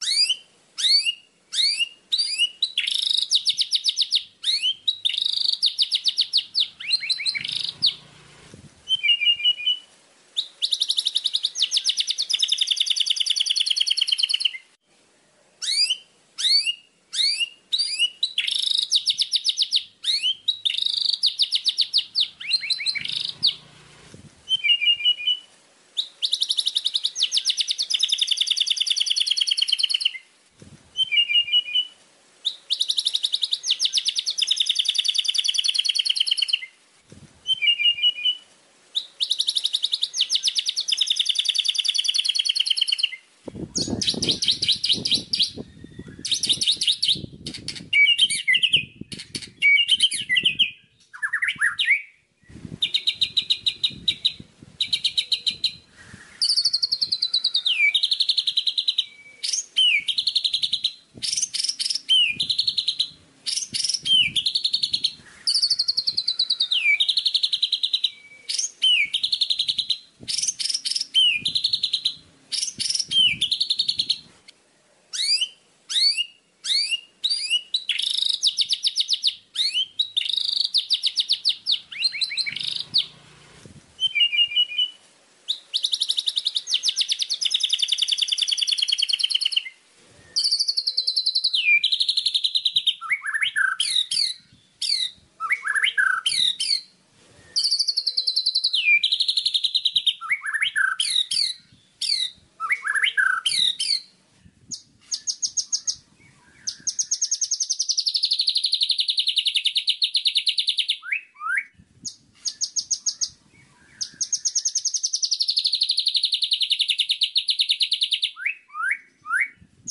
12 Suara Masteran Murai Batu
Genre: Nada dering binatang Tag: nada dering binatang , nada dering burung bersiu Ukuran file: 12 MB Dilihat: 4727 Views / 156 Downloads Detail: Tidak ada deskripsi tersedia.
12-suara-masteran-murai-batu.mp3